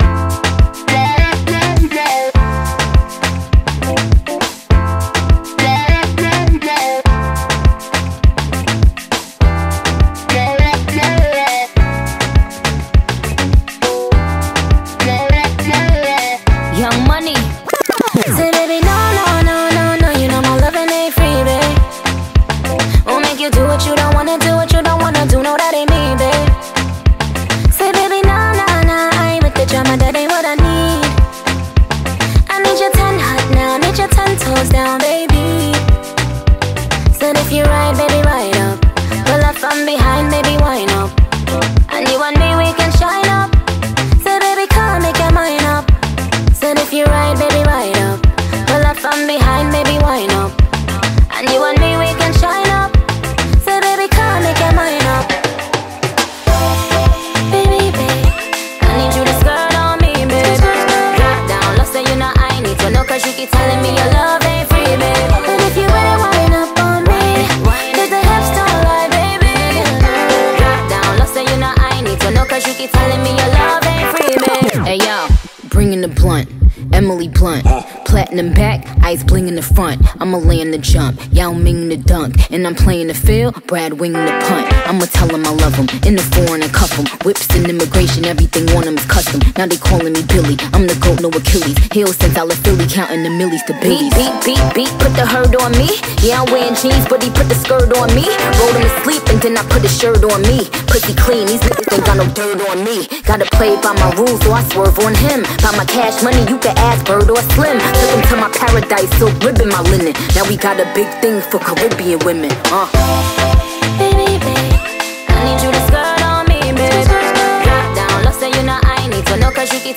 BPM102
MP3 QualityMusic Cut